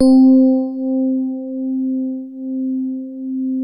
FINE SOFT C3.wav